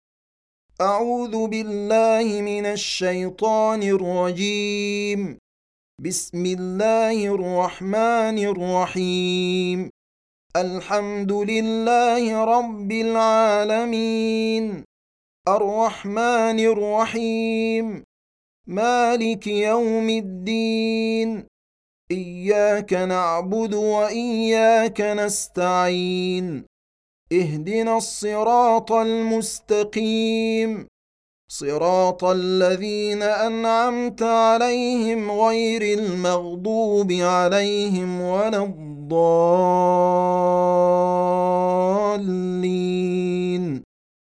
1. Surah Al-F�tihah سورة الفاتحة Audio Quran Tarteel Recitation
Surah Repeating تكرار السورة Download Surah حمّل السورة Reciting Murattalah Audio for 1.